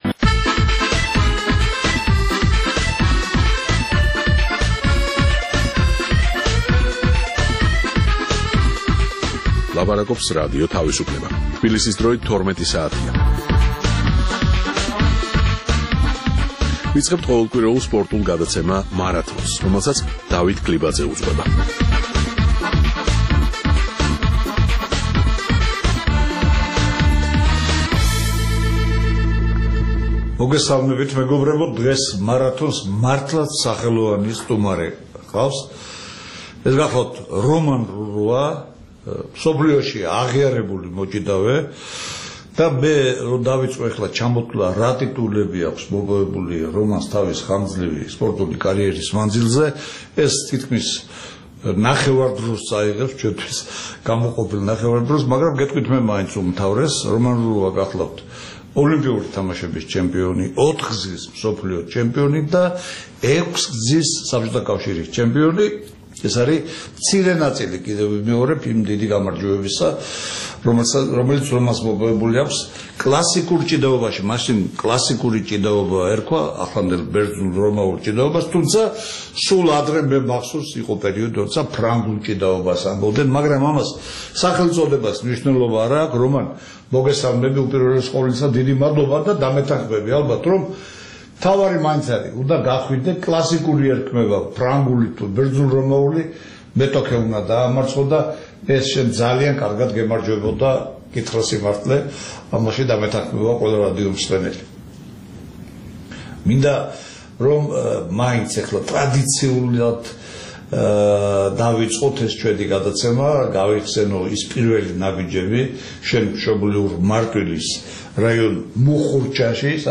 გადაცემის სტუმარი, ოლიმპიური და მსოფლიოს არაერთგზის ჩემპიონი, რომან რურუა რადიომსმენელებს შეახსენებს თავისი სახელოვანი სპორტული ცხოვრების რამდენიმე ეპიზოდს.